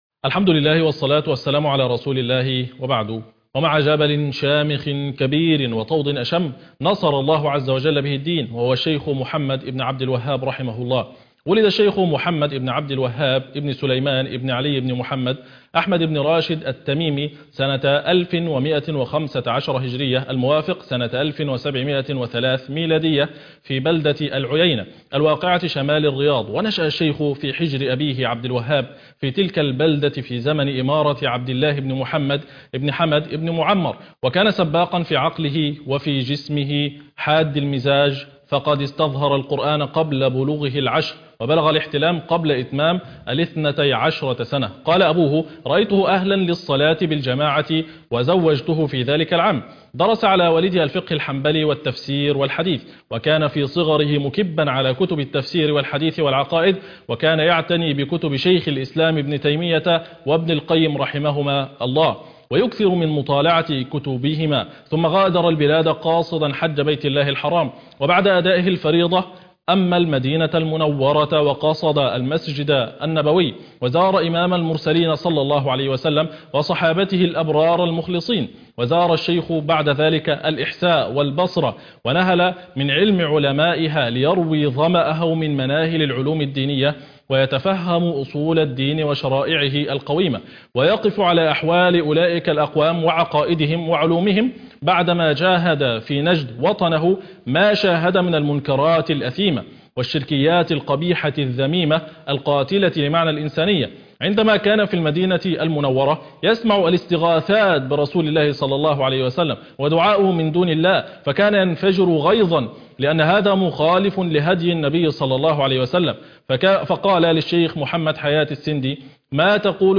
المقرأة - سورة يوسف ص 239